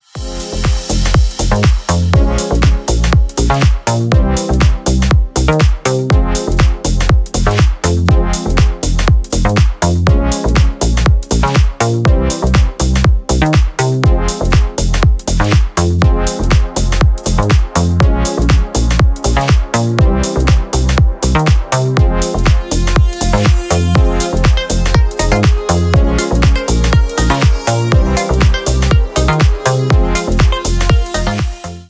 Ремикс
Поп Музыка